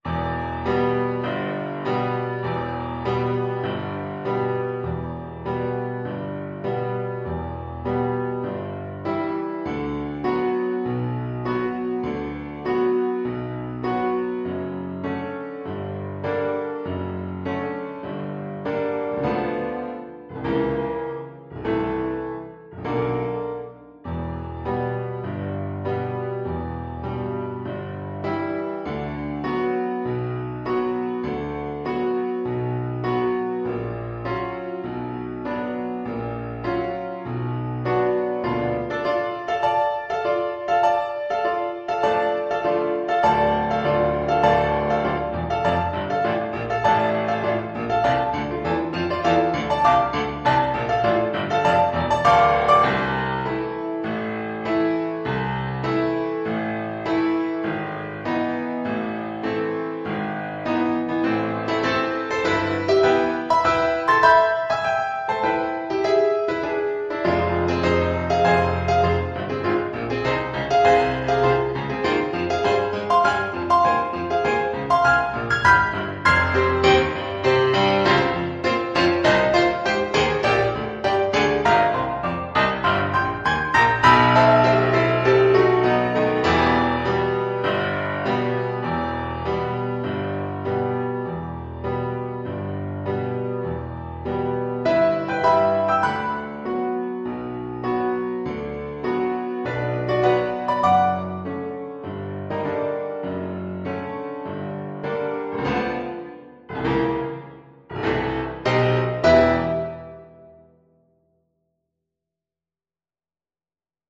Classical (View more Classical Violin Music)